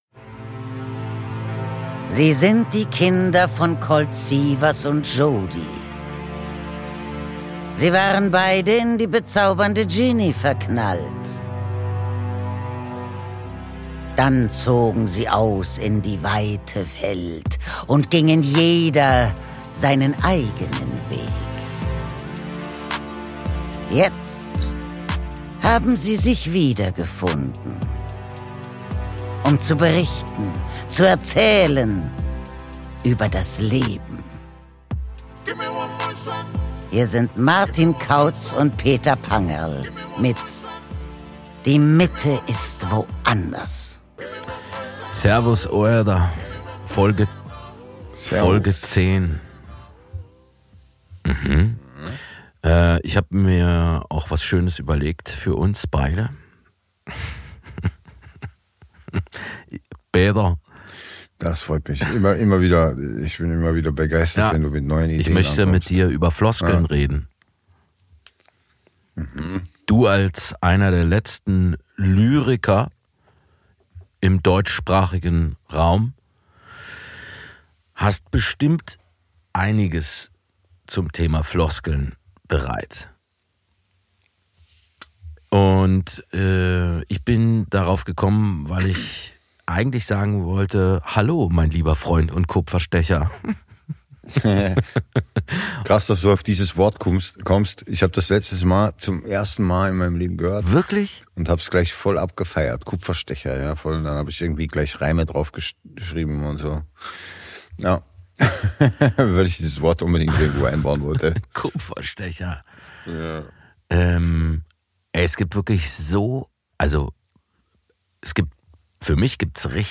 Die beiden entwickeln immer mehr eine Linie Ihrer Sendung und könnten nicht besser vom Leder ziehen.